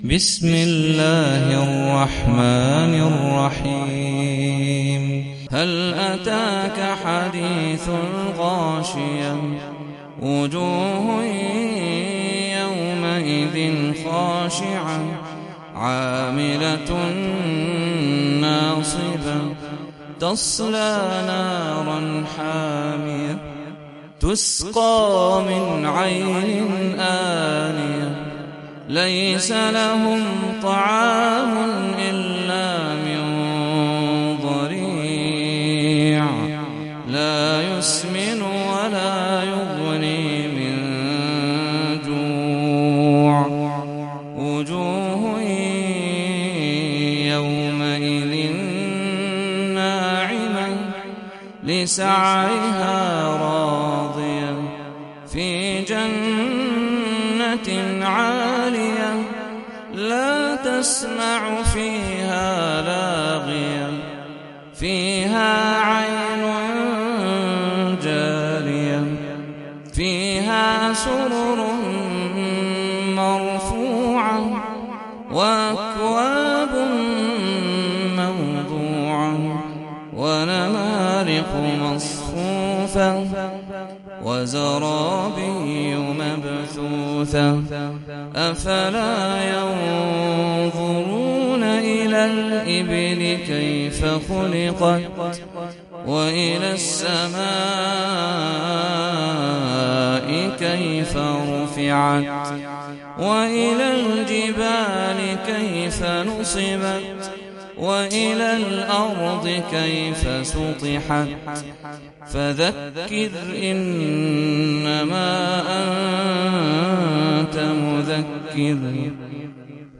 سورة الغاشية - صلاة التراويح 1446 هـ (برواية حفص عن عاصم)
جودة عالية